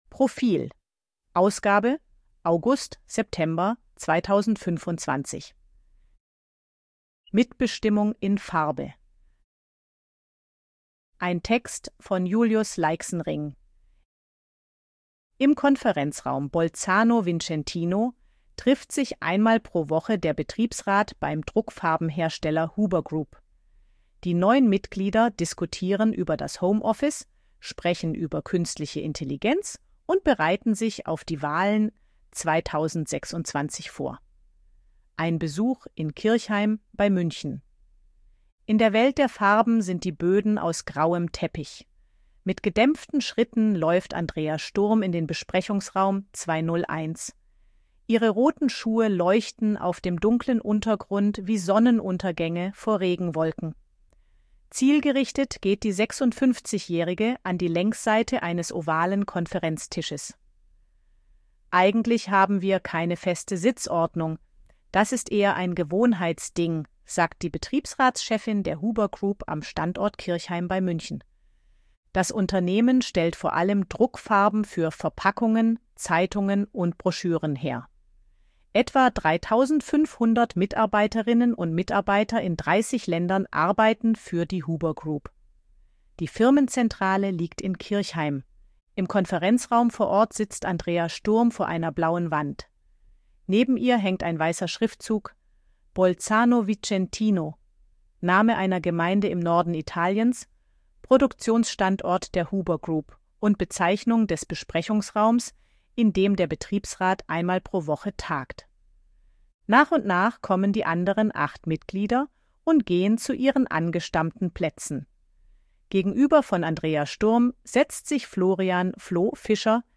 Artikel von KI vorlesen lassen ▶ Audio abspielen
ElevenLabs_252_KI_Stimme_Frau_Portrait_v2.ogg